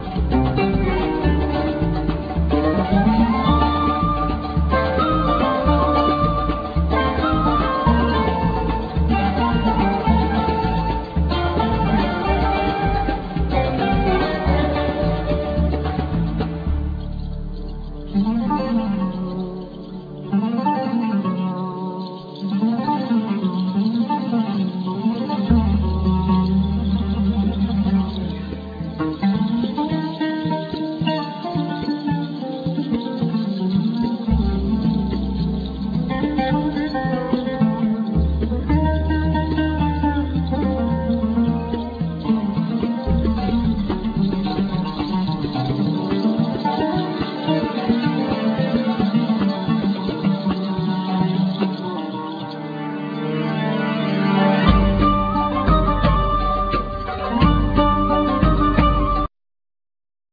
Kanun
Rek,Duf,Zilia,Durbakkeh
Double Bass
Ney
Tamboura
Daul
Oud,Vocals,Percussions,Keyboards
Drums